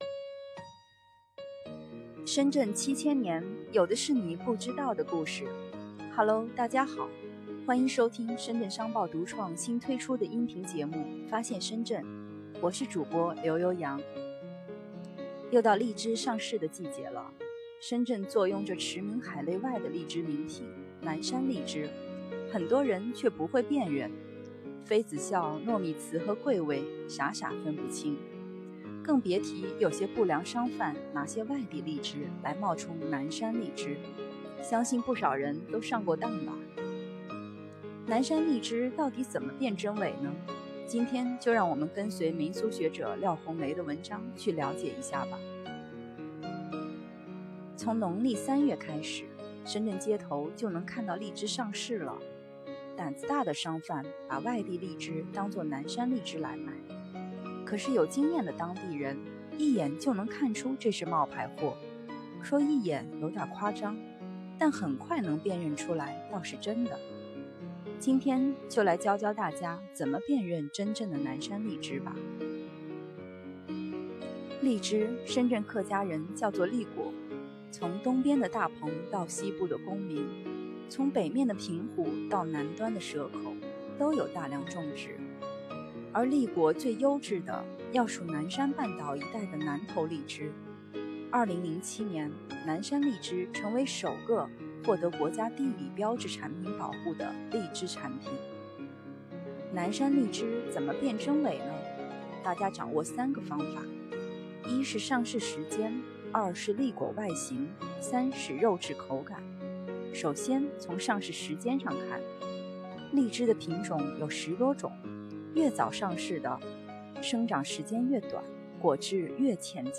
【主播】